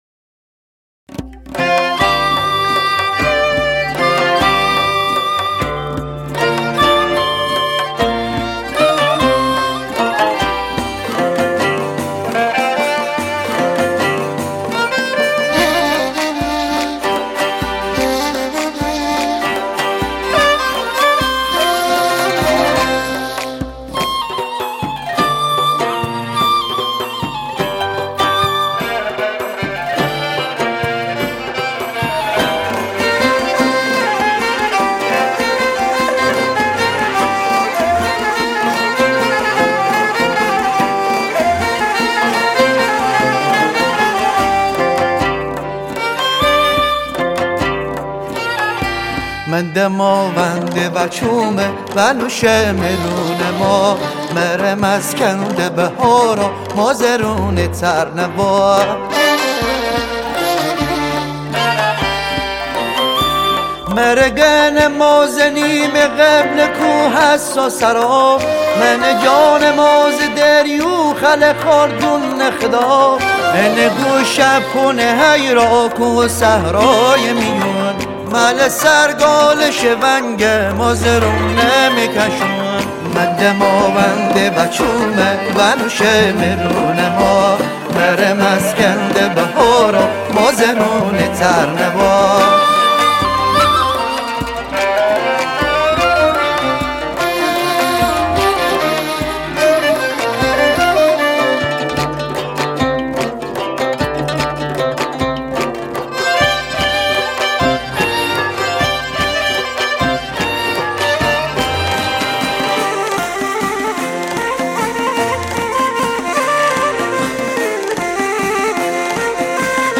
سنتی
اصیل
آهنگی در سبک آهنگ های اصیل و شاد مازندرانی
تنبک